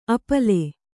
♪ apale